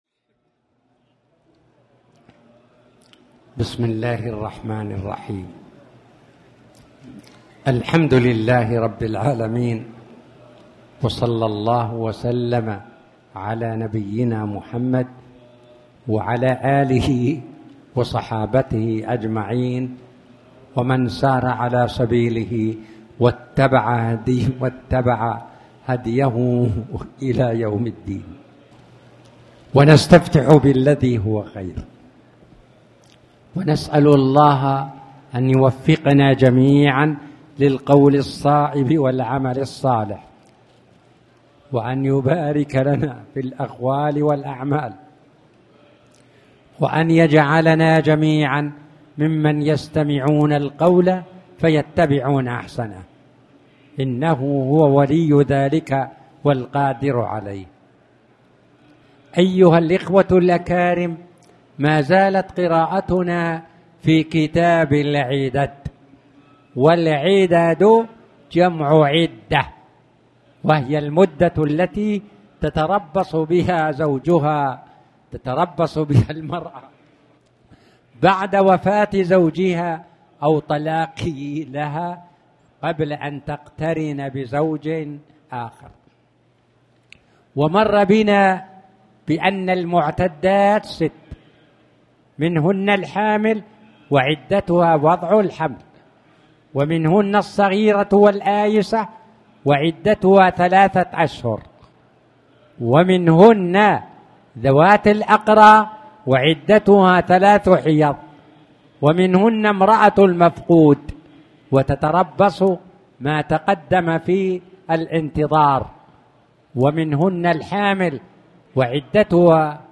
تاريخ النشر ٤ صفر ١٤٣٩ هـ المكان: المسجد الحرام الشيخ